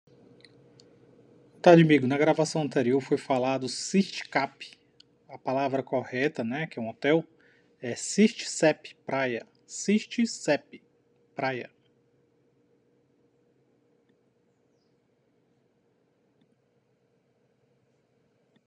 Favor interpretar o texto com emoção e entusiasmo
Foi falado Sistcap, o Correto é Sistcep